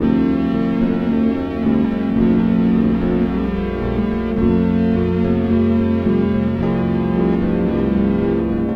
ceaseless_now_loop.mp3